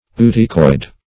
Search Result for " ooticoid" : The Collaborative International Dictionary of English v.0.48: Ooticoid \O*ot"i*coid\, Ootocoid \O*ot"o*coid\, n. [Gr.